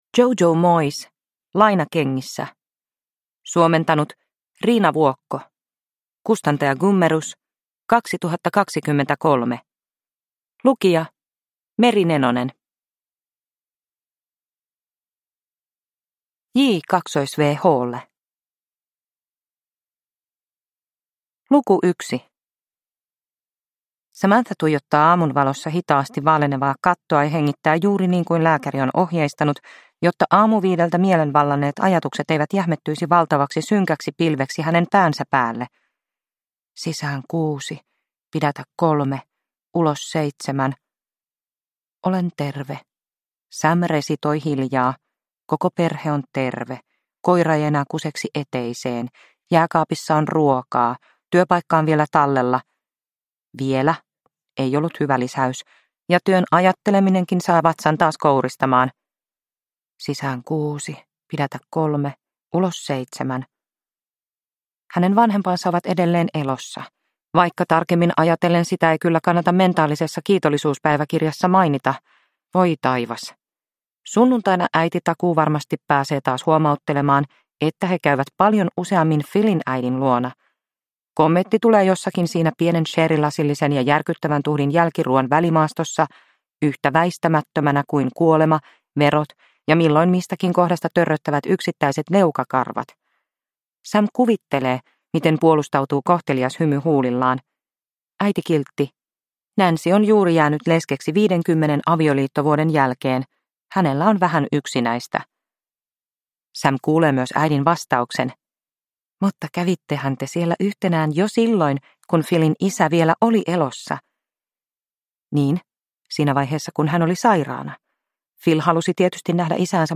Lainakengissä – Ljudbok – Laddas ner